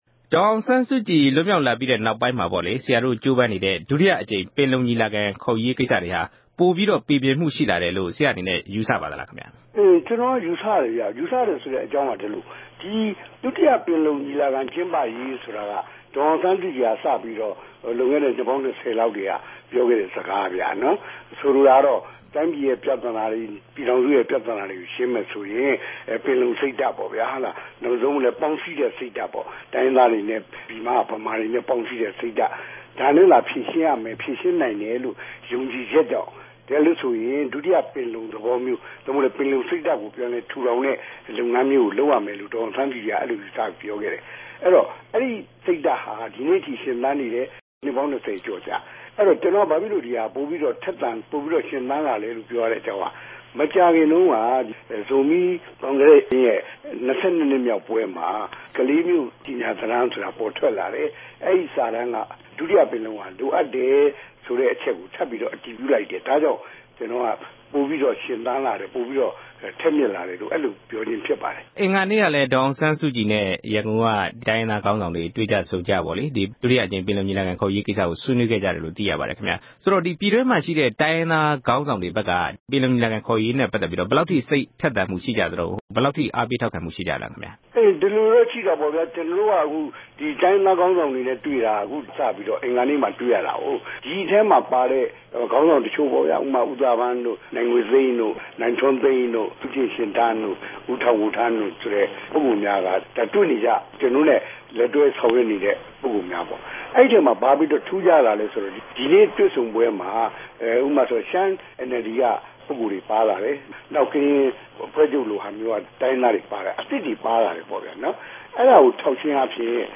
ဒေါက်တာ အေးမောင်နှင့် ဆက်သွယ်မေးမြန်းချက်။